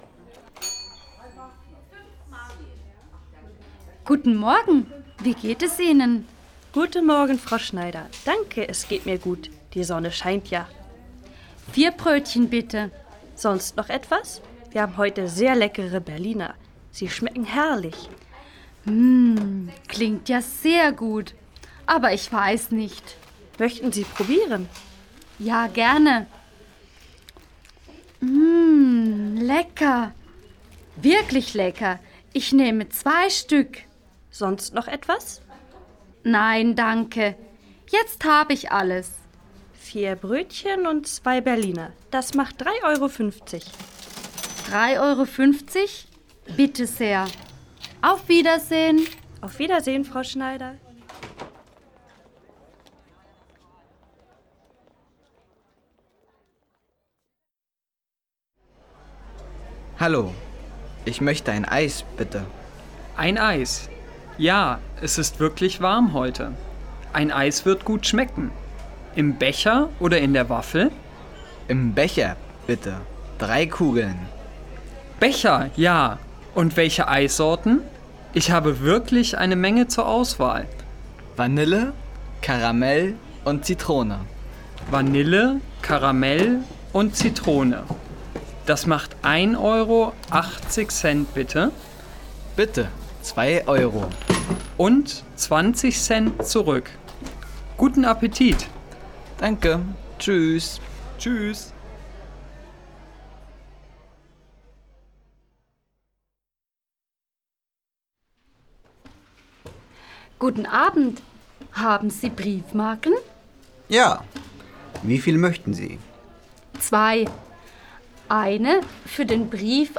Lytteprøve